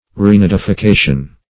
Search Result for " renidification" : The Collaborative International Dictionary of English v.0.48: Renidification \Re*nid`i*fi*ca"tion\ (r?-n?d`?-f?-k?"sh?n), n. (Zool.)